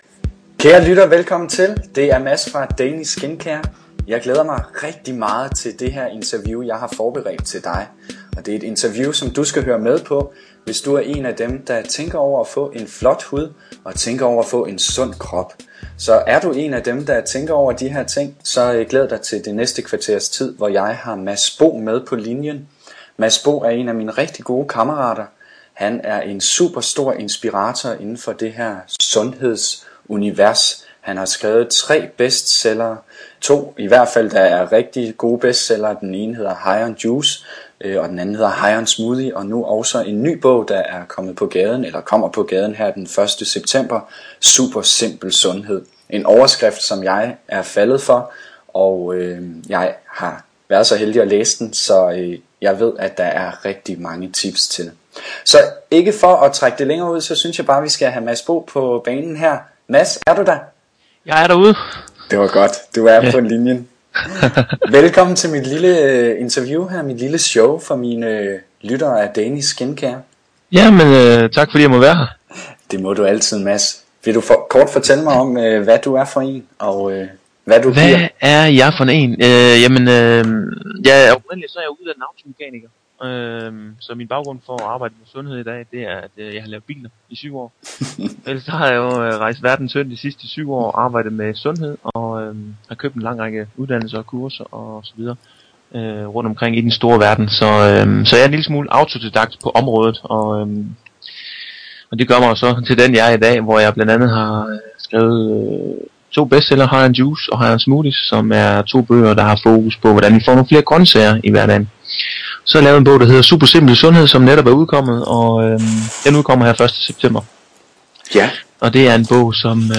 På et tidspunkt interviewede jeg nemlig kost- og sundhedsekspert